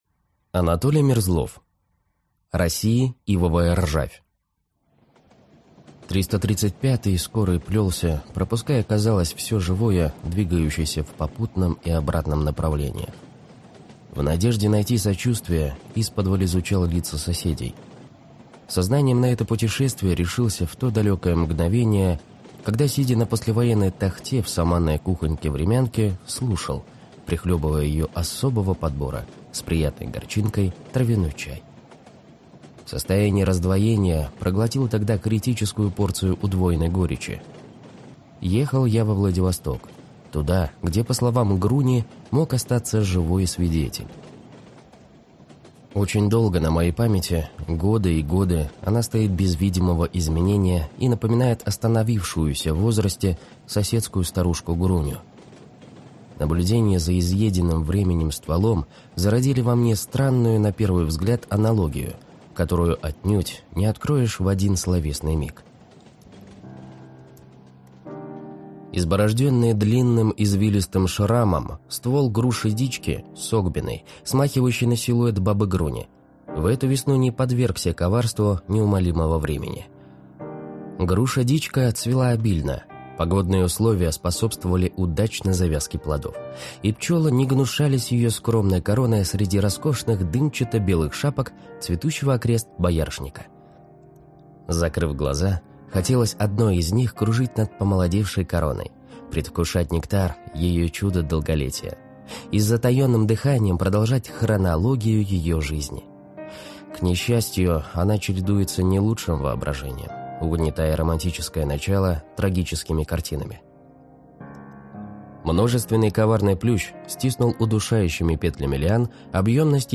Аудиокнига России ивовая ржавь | Библиотека аудиокниг
Прослушать и бесплатно скачать фрагмент аудиокниги